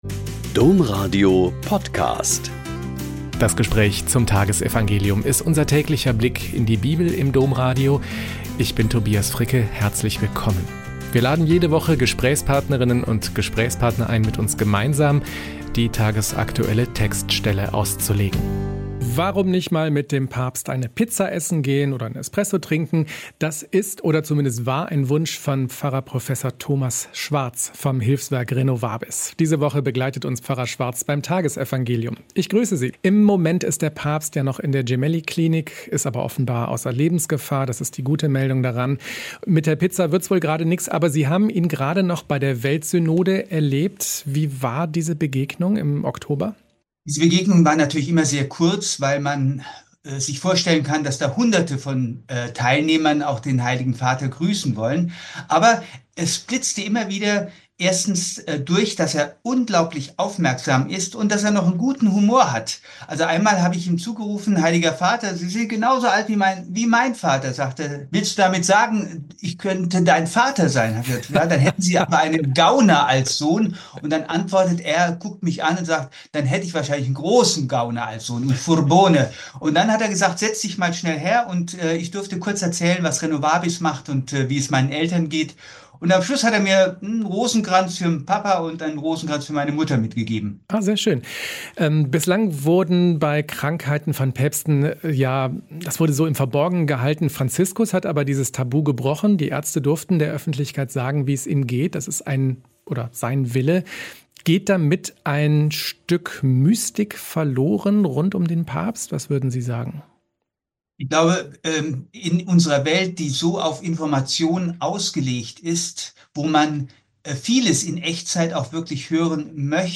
Lk 1, 29-32 - Gespräch